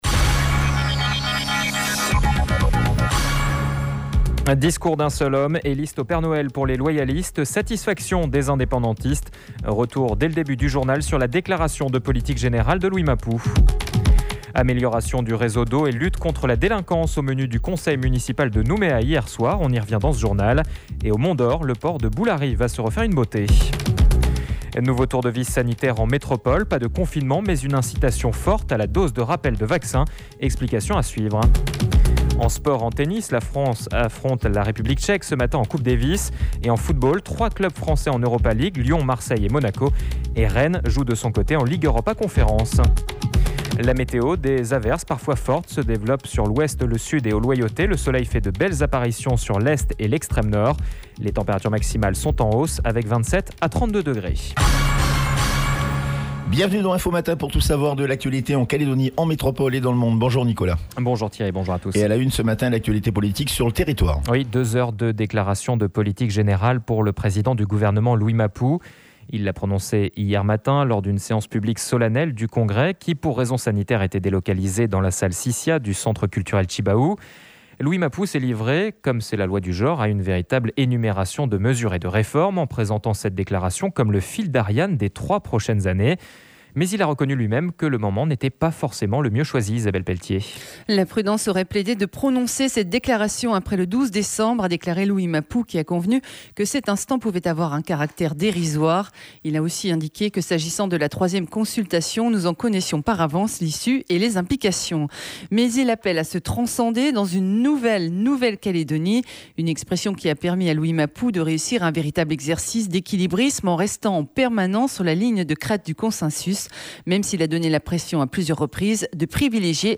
JOURNAL : VENDREDI 26/11/21 (MATIN)